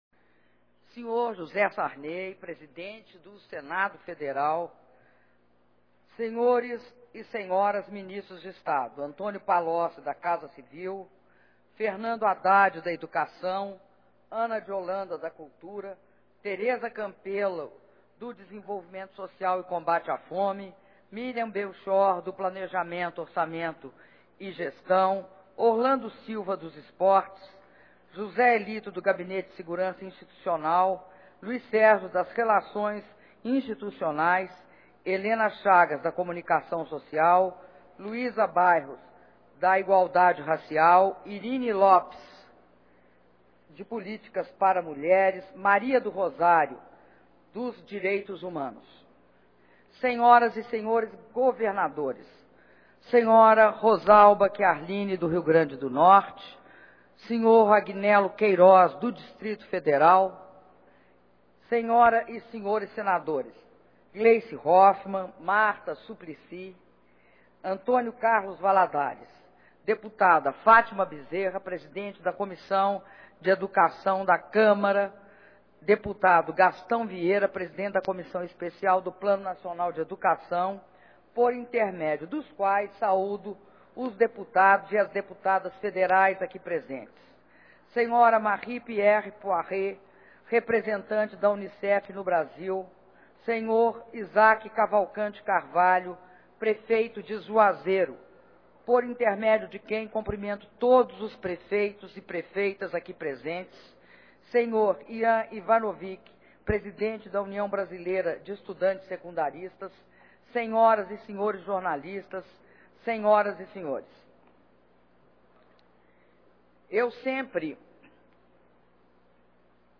Discurso da Presidenta da República, Dilma Rousseff, durante cerimônia de assinatura de termos de compromisso para construção de quadras esportivas e unidades de educação infantil e doação de bicicletas - Brasília/DF
Palácio do Planalto, 26 de maio de 2011